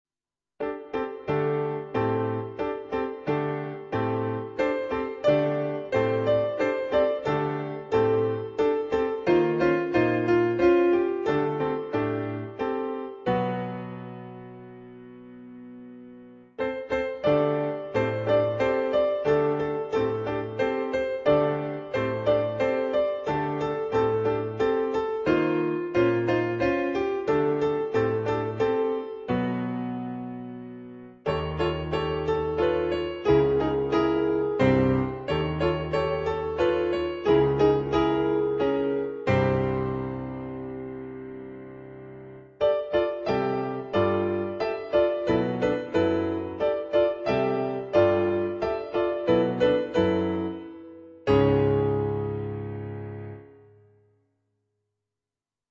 adapted for Two Pianos
on Yamaha digital pianos.